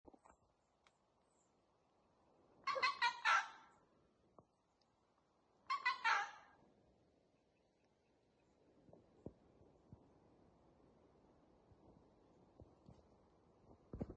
Red-throated Caracara (Ibycter americanus)
Bandada de 6 a 8 individuos
Location or protected area: Parque Nacional Yanachaga Chemillén
Condition: Wild
Certainty: Observed, Recorded vocal